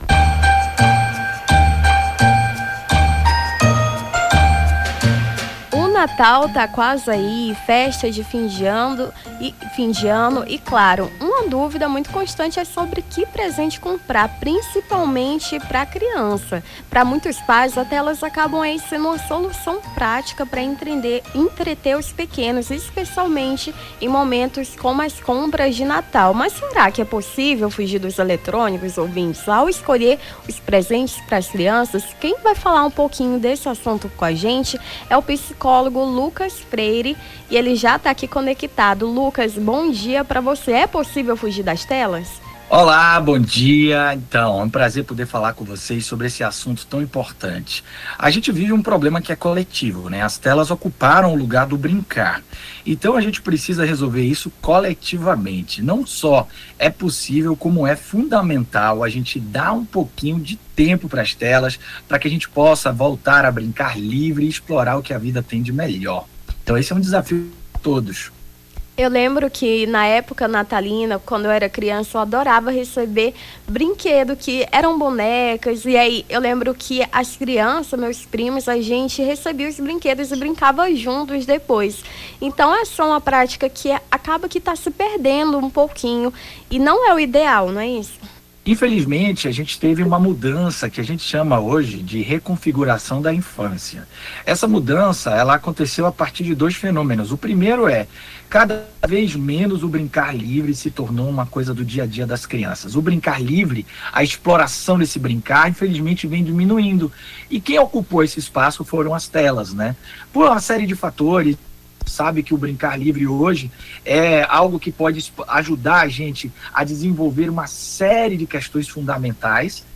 Nome do Artista - CENSURA - ENTREVISTA PRESENTEAR CRIANÇAS (16-12-24).mp3